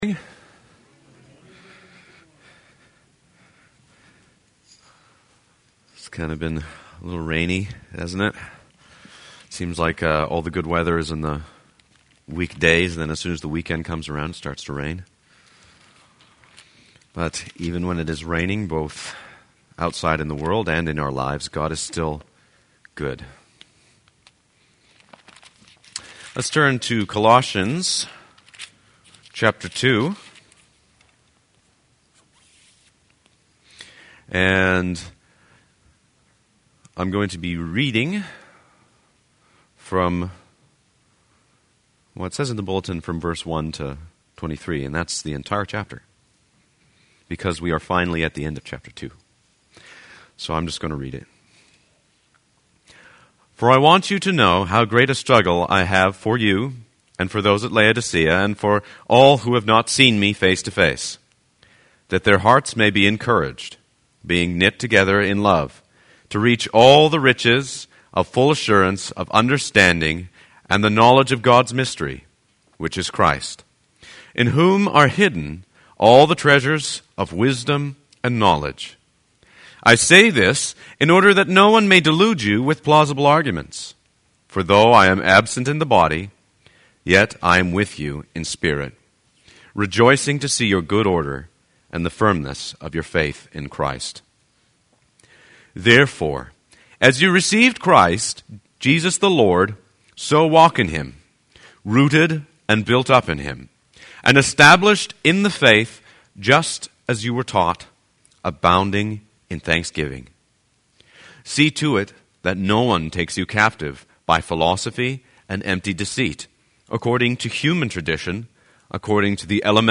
Sermon Downloads: May 2014